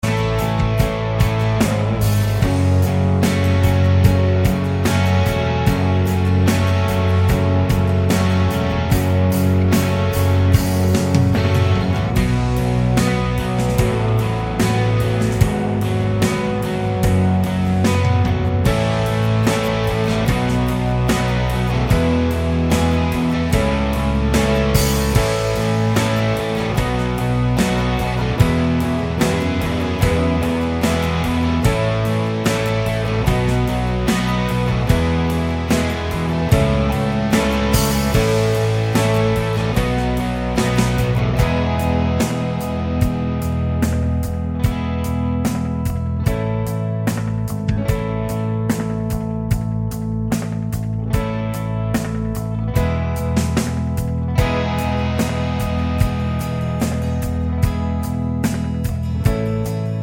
Minus Main Guitar Pop (1990s) 3:49 Buy £1.50